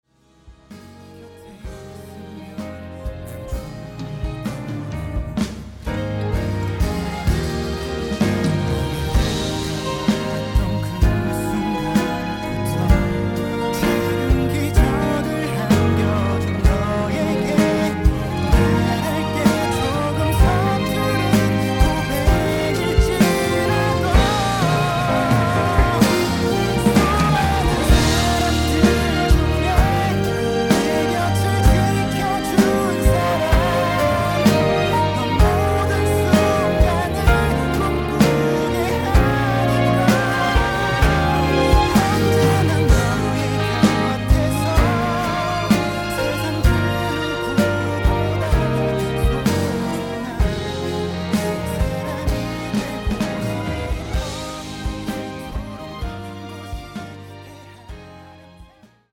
음정 원키 4:30
장르 가요 구분 Voice Cut